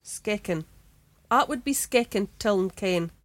[sKEK-an: at wid be sKEKan tiln ken?]